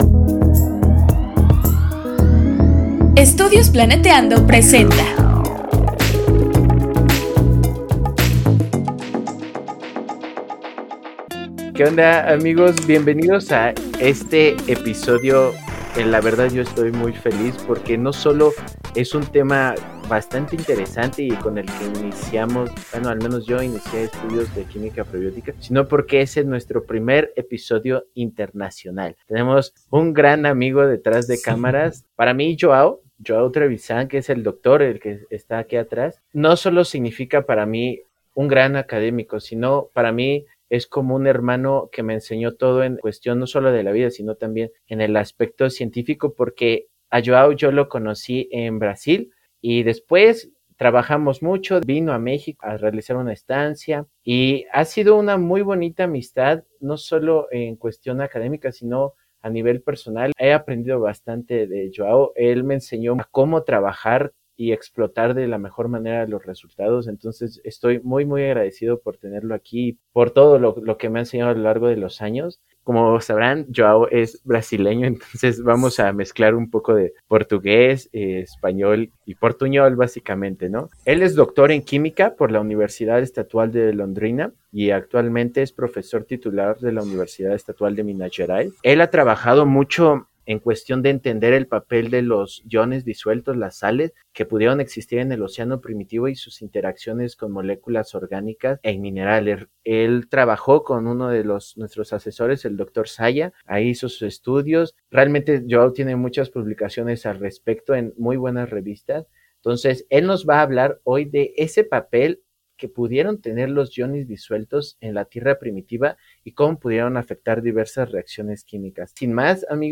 Desde Brasil, hablamos de la importancia de considerar sales en los experimentos de química prebiótica y sus aplicaciones. Entrevista